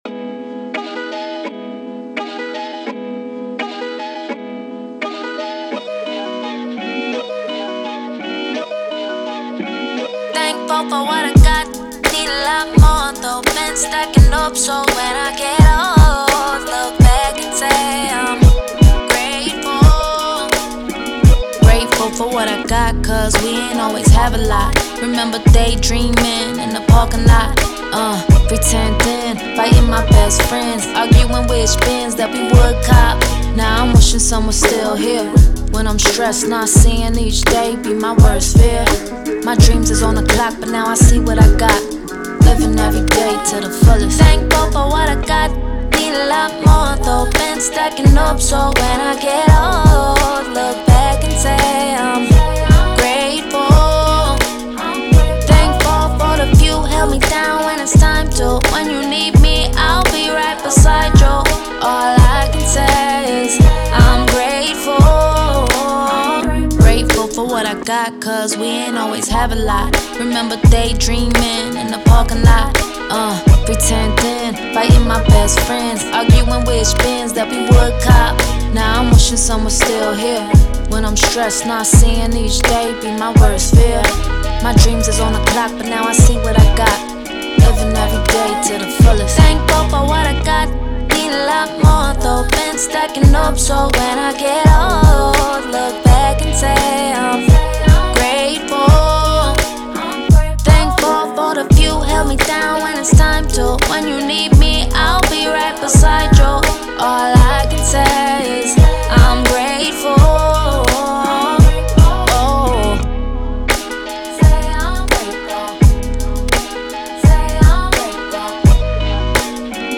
R&B, Hip Hop
Ab Minor